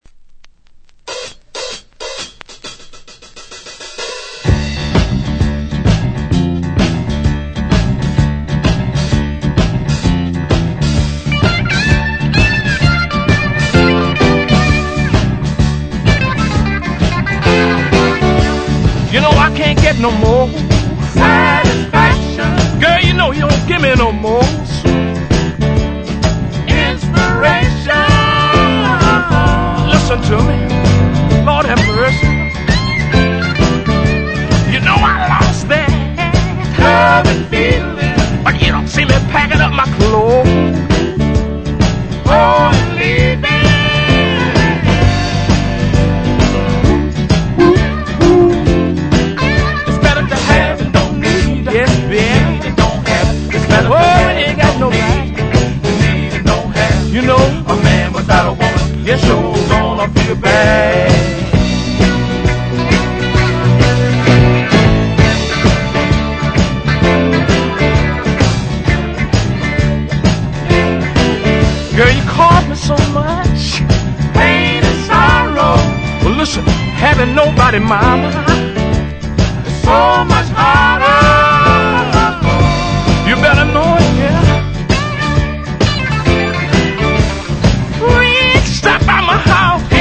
Genre: SOUL ORIG / REISS